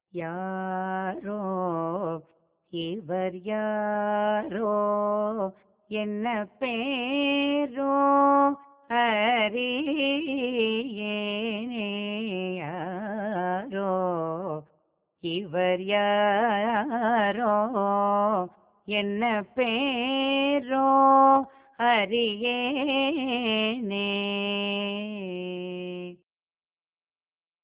இராகம் : பைரவி தாளம் : ஆதி